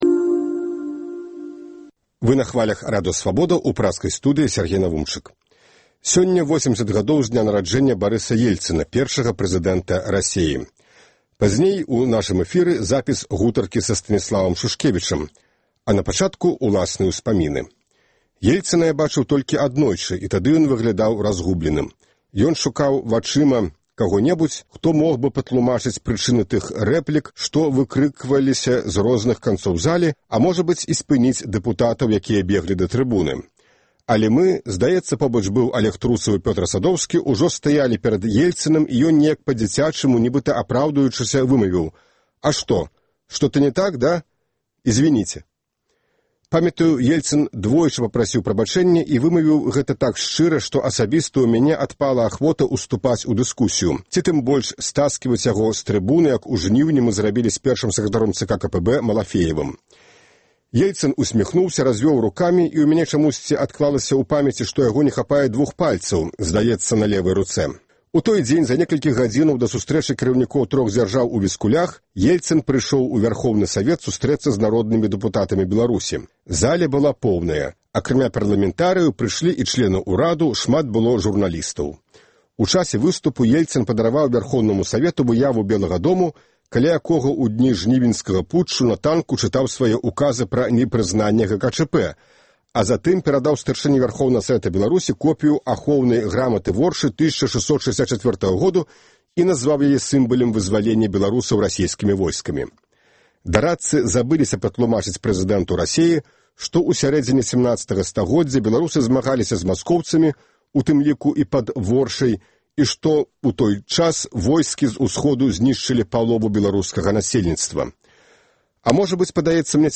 Успаміны Сяргея Навумчыка. Інтэрвію з Станіславам Шушкевічам.